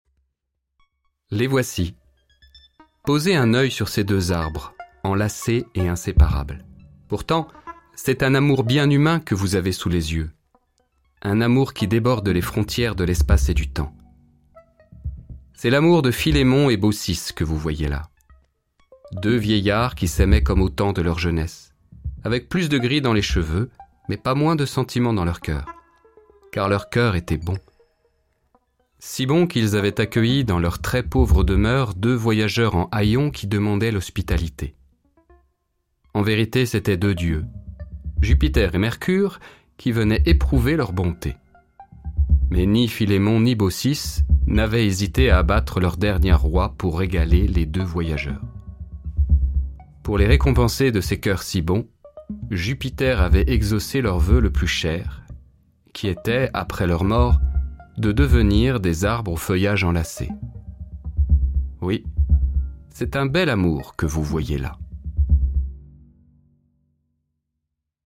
Conte . Mythe . Europe
Cette balade contée prend place autour du quartier européen.
Une fiction sonore disponible sur l’application dédiée – GOH